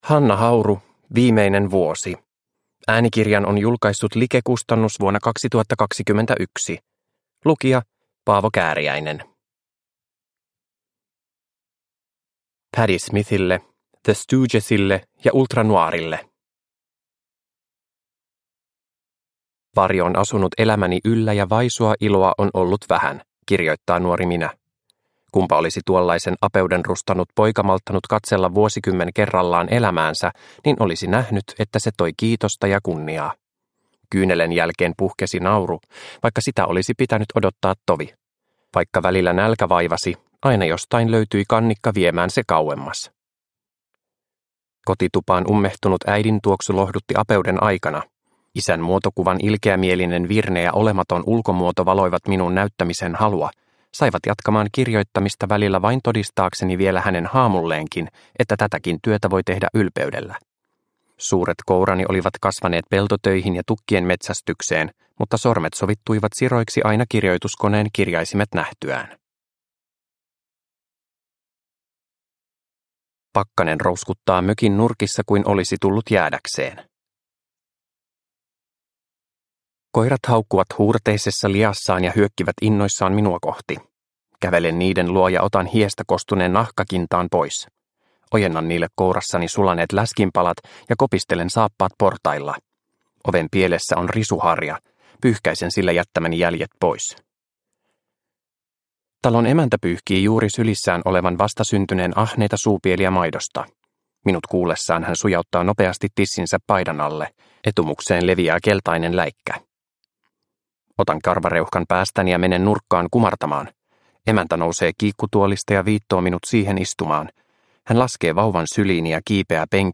Viimeinen vuosi – Ljudbok – Laddas ner